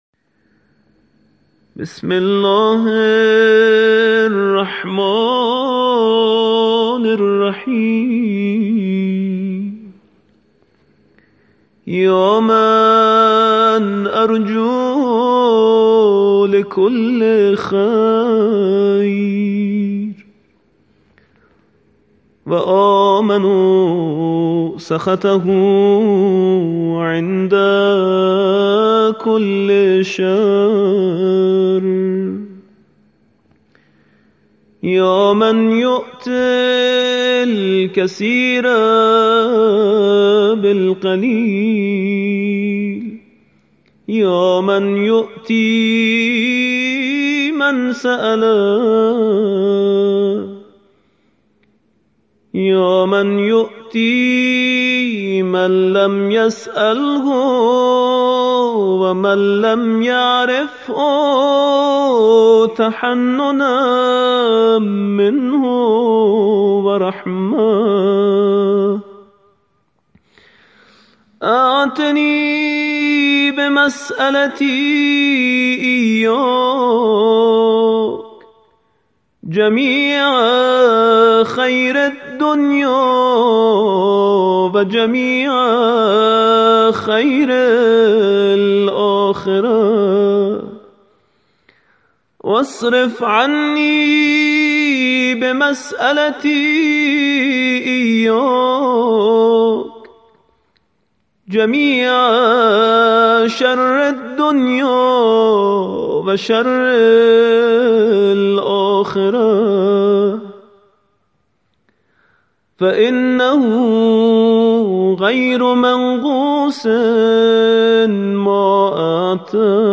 قاری ممتاز کشور